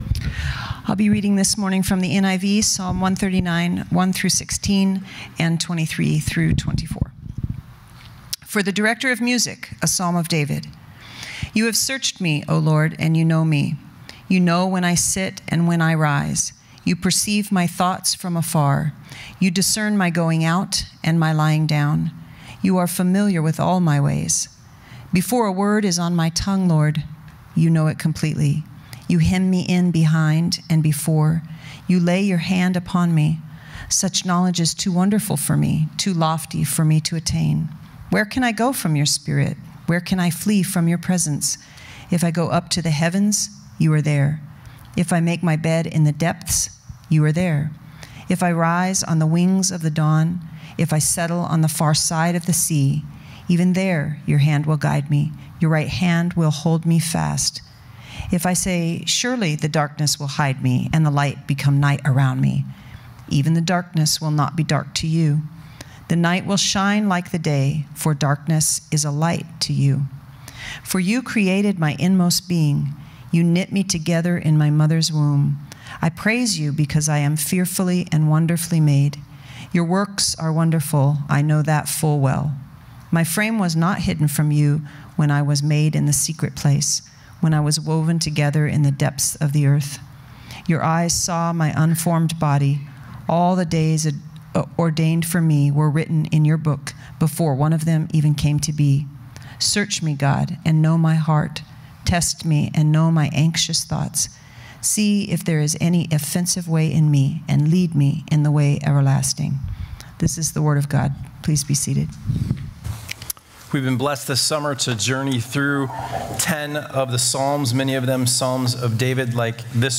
This Sunday (Sept. 4) will be a special service featuring extended times of worship and prayer, along with reflections on Psalm 139. This beautiful text shows us three expressions of God’s infinite love for us: 1) He knows us perfectly; 2) He protects us completely and; 3) He is with us forever.